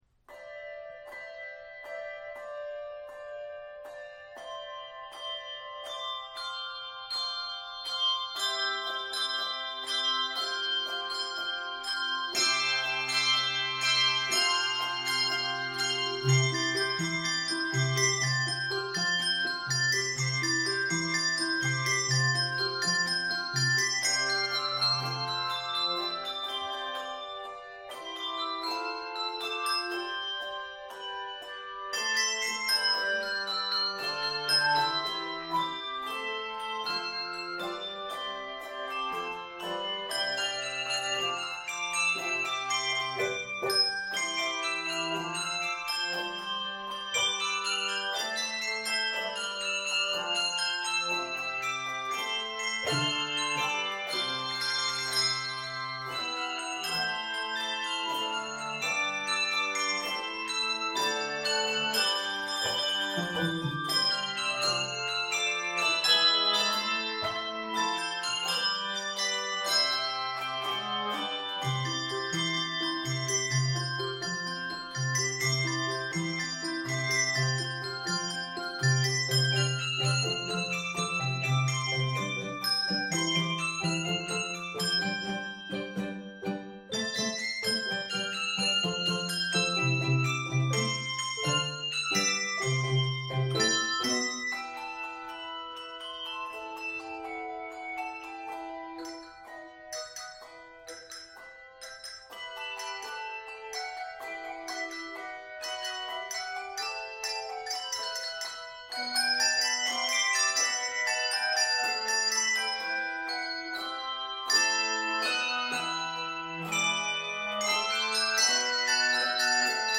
The simple but electrifying rhythms
Keys of C Major and G Major.